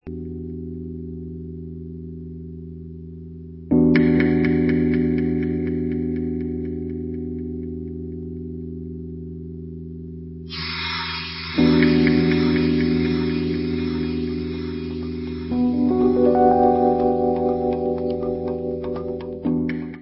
sledovat novinky v oddělení Dance/Drum & Bass